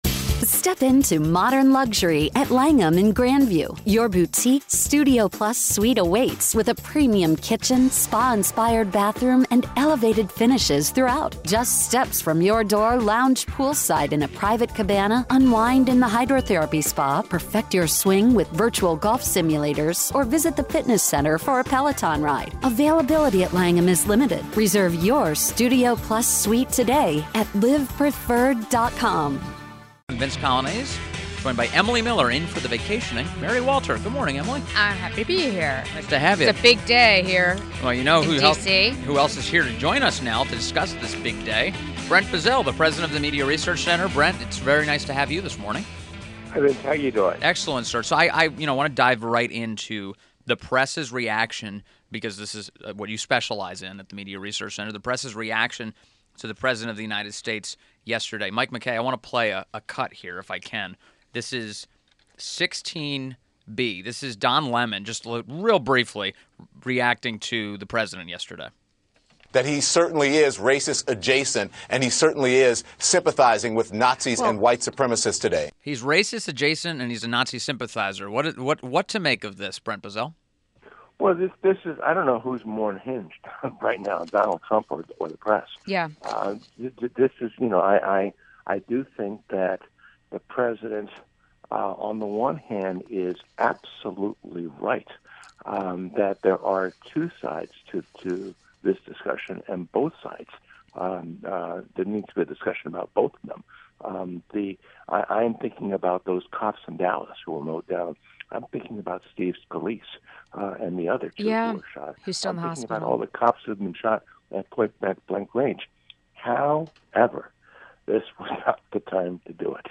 INTERVIEW – BRENT BOZELL – President of the Media Research Center